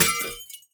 glass1.ogg